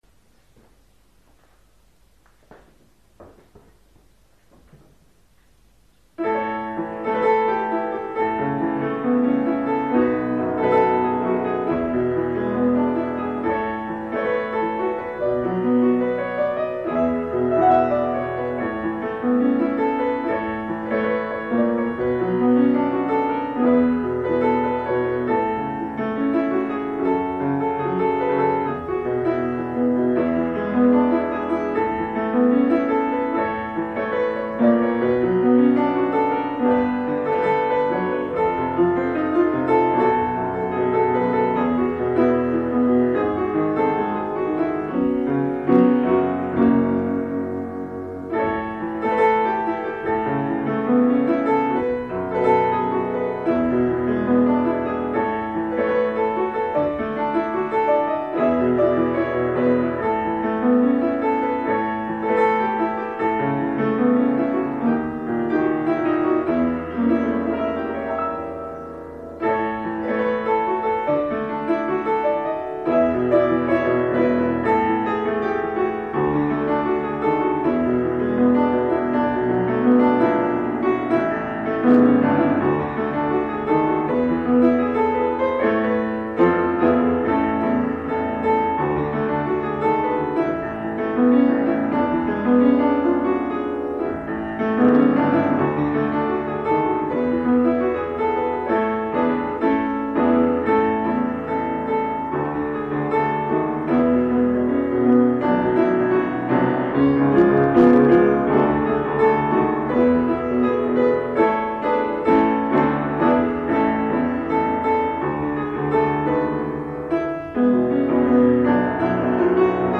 נגינה טובה, שליטה טובה בכלי.
לגבי ההקלטה - לא נעים - אבל האיכות זוועה.